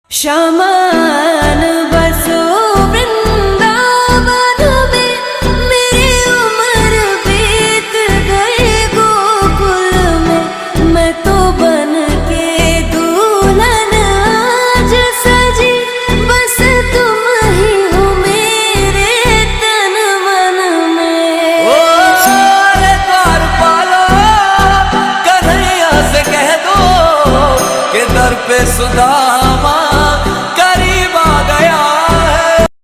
krishna bansuri ringtone mp3 download